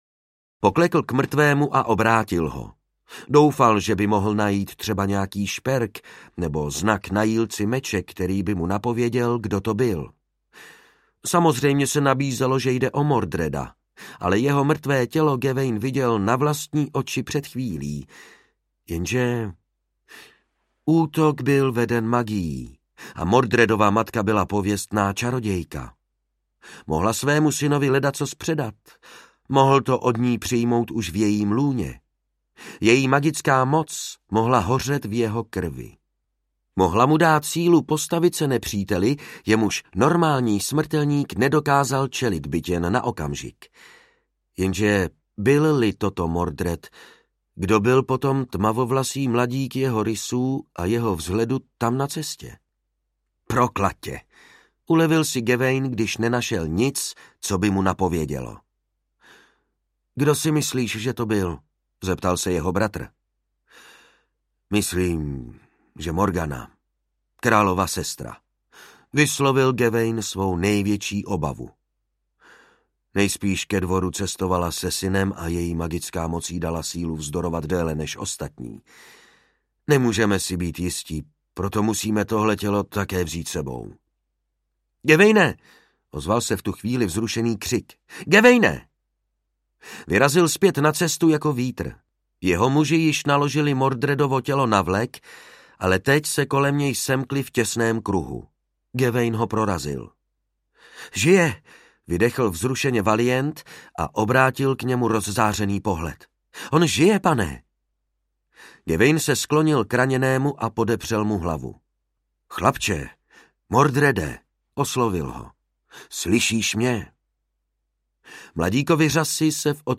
Ukázka z knihy
soumrak-camelotu-audiokniha